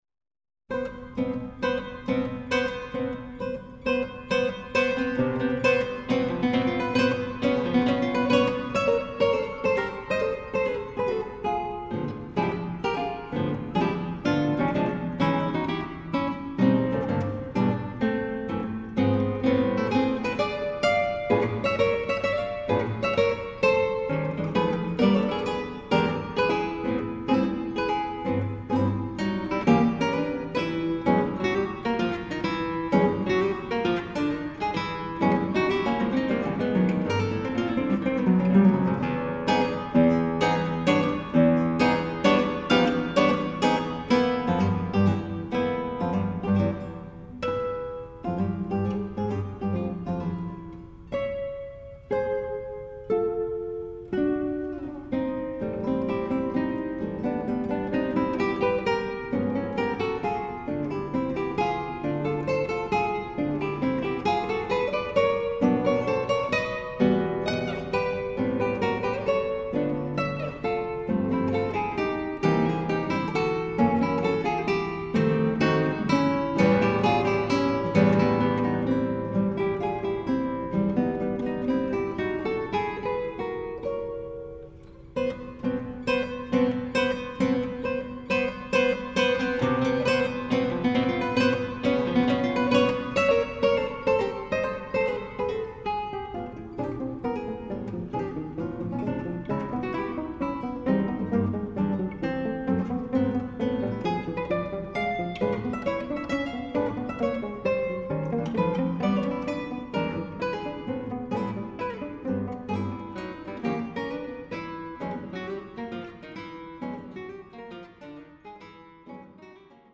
Guitar
tongue-in-cheek musical parodies